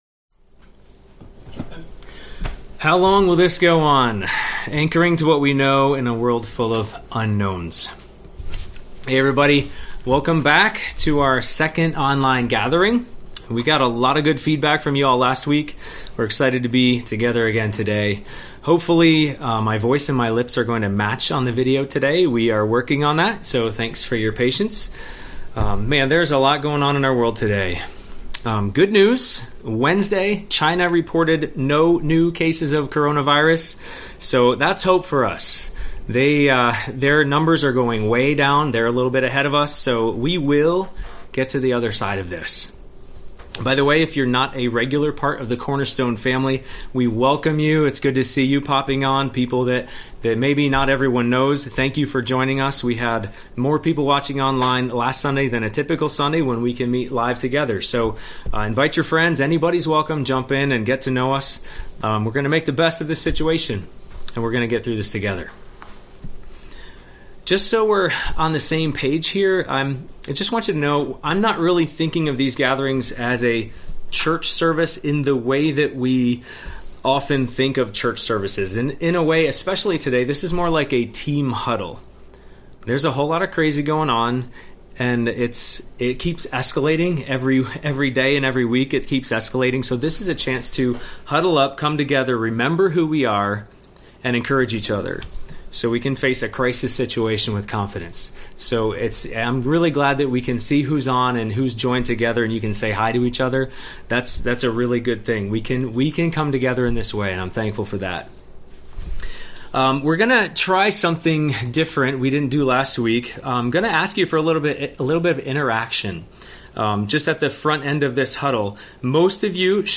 Psalm 77 Service Type: Sunday Morning Bible Text